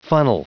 Prononciation du mot funnel en anglais (fichier audio)
Prononciation du mot : funnel